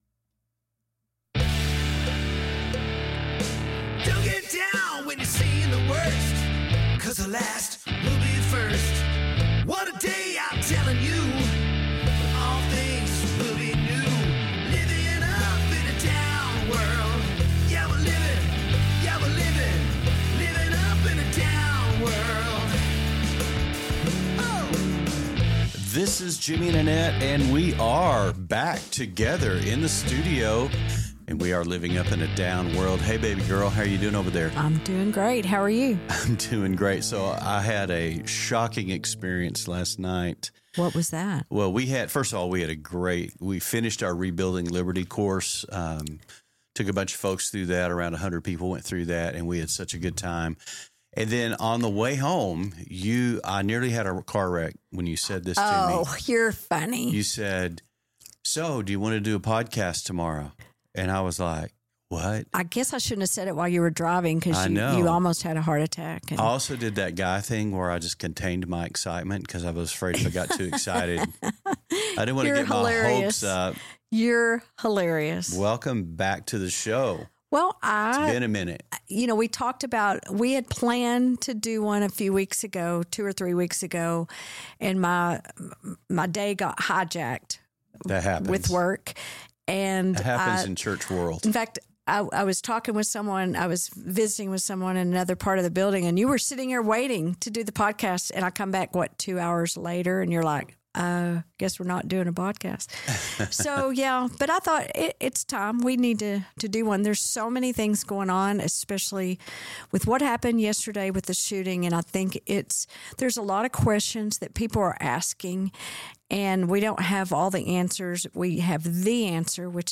Live at Bridge Church (Part 2). The topic is Faith - Family - Freedom.